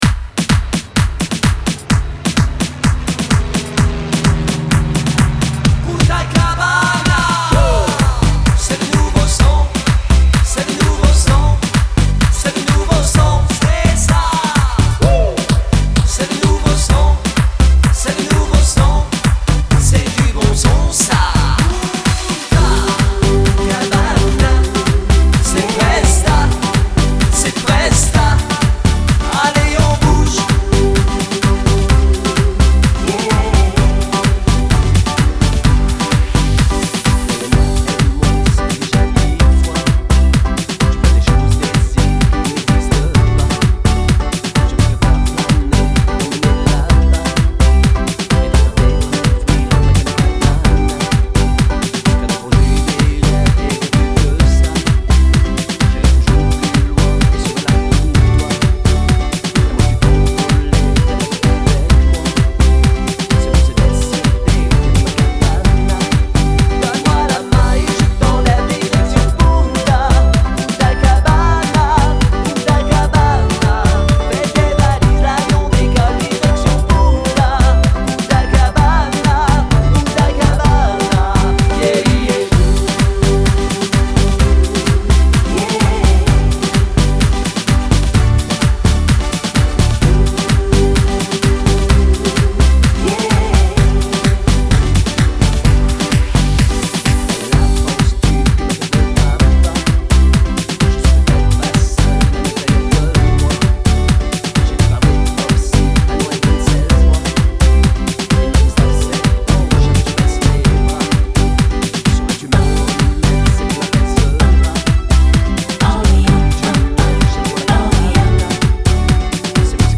DANSE EN LIGNE